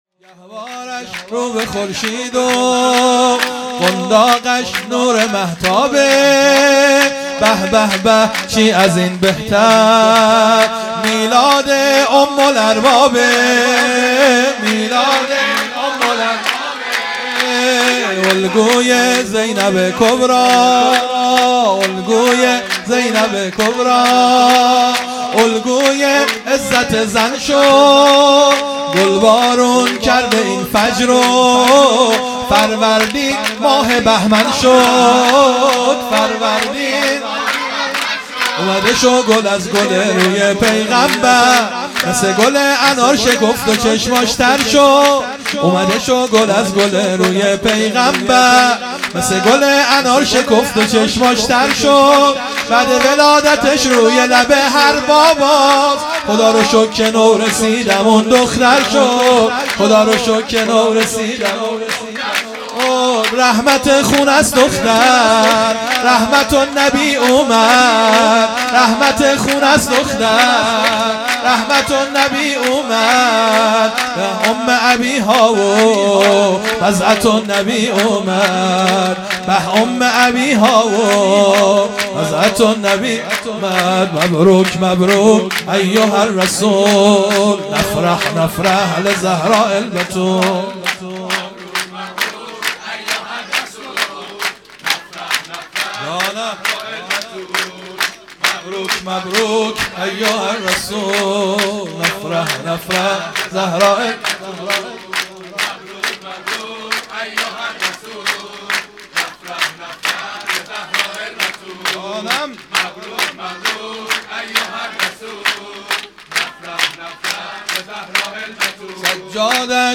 سرود ۱